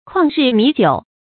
曠日彌久 注音： ㄎㄨㄤˋ ㄖㄧˋ ㄇㄧˊ ㄐㄧㄨˇ 讀音讀法： 意思解釋： 曠：耽擱荒廢。指時間拖得很久。